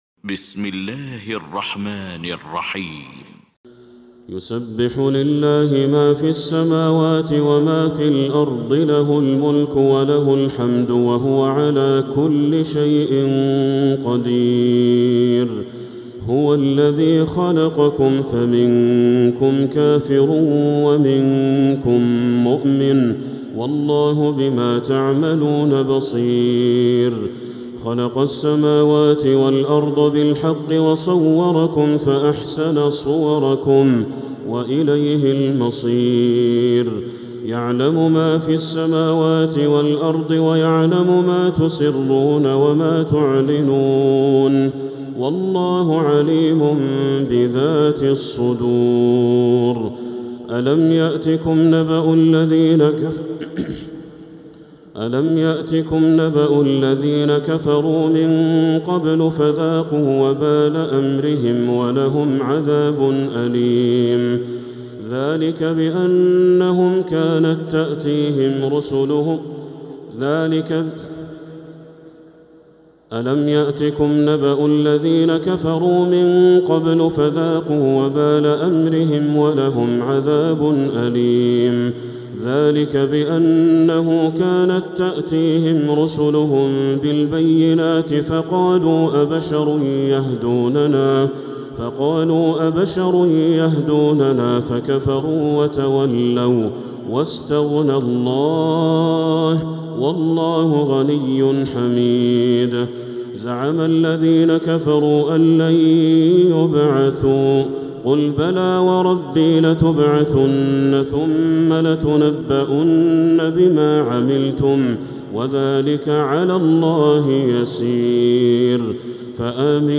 المكان: المسجد الحرام الشيخ: بدر التركي بدر التركي التغابن